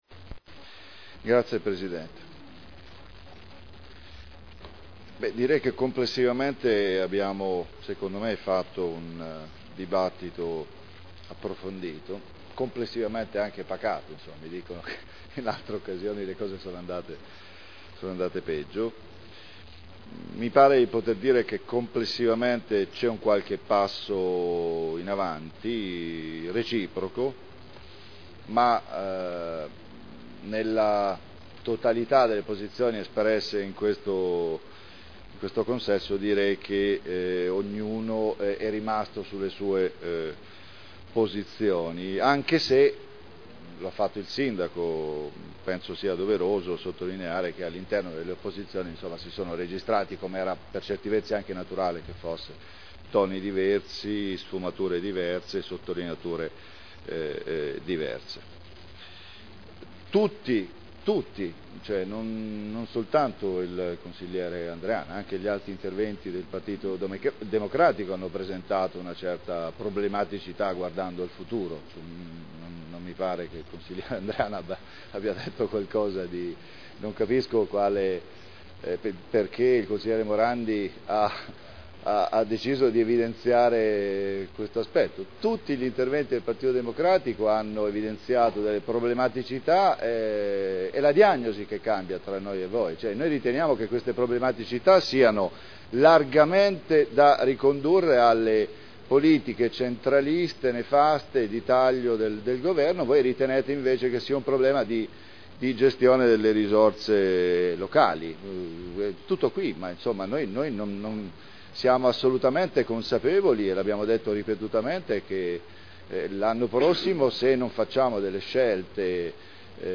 Dichiarazioni di voto sul bilancio
Seduta del 08/02/2010